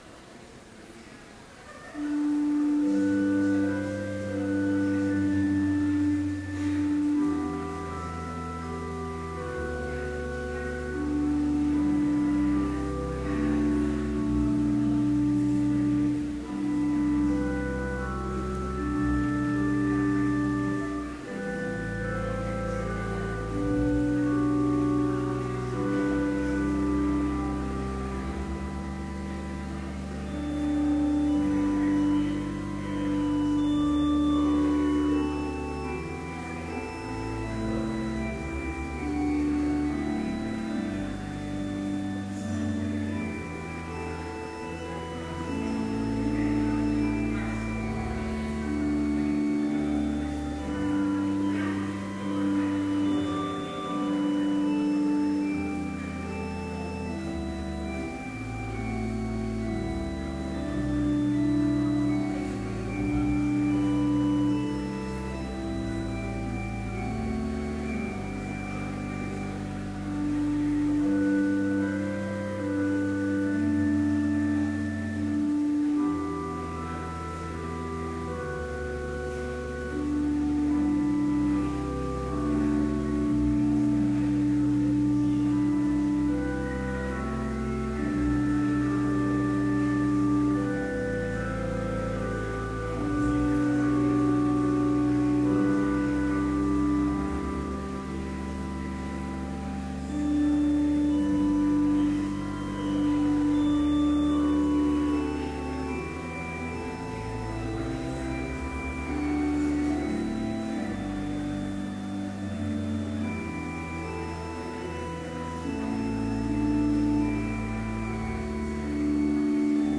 Complete service audio for Chapel - May 8, 2013